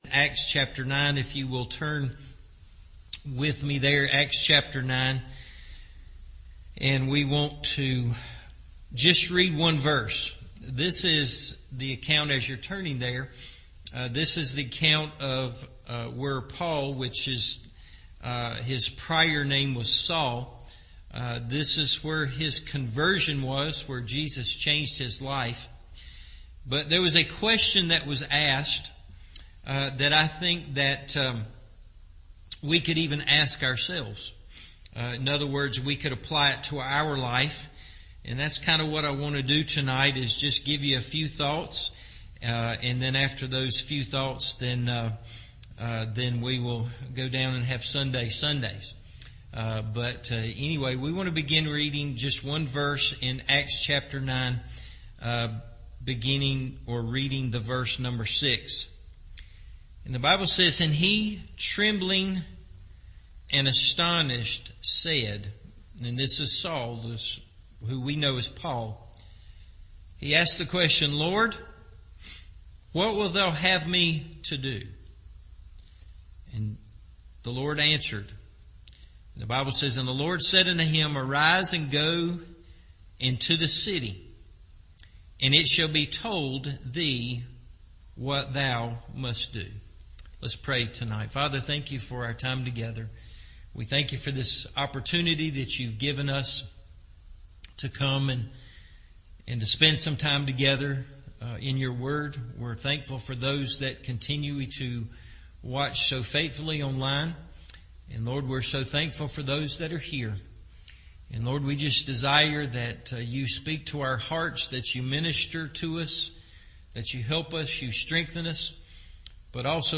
God’s Plan For Our Lives – Evening Service